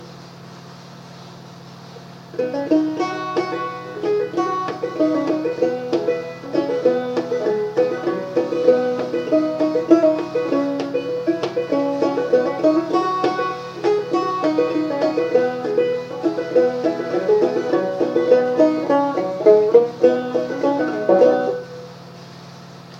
bury_me_banjo.mp3